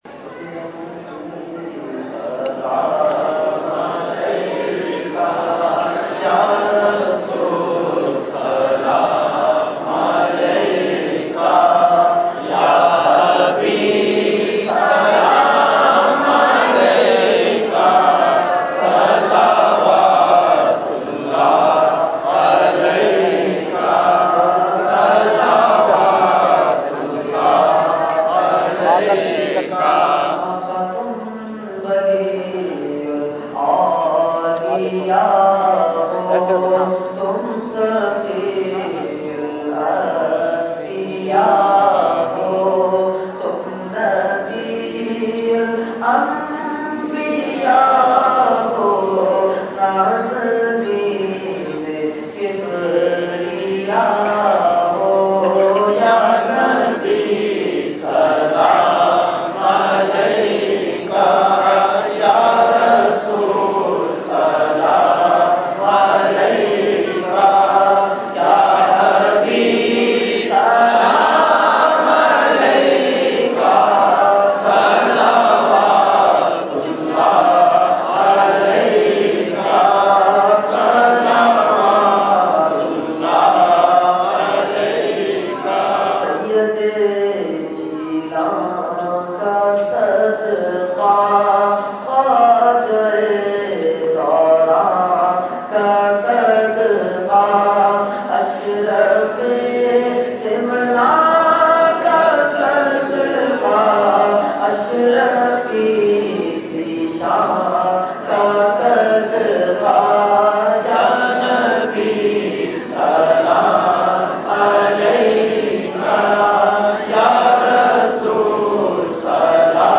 Category : Salam | Language : UrduEvent : Mehfil Milad Ghousia Masjid Liaqatabad 25 January 2014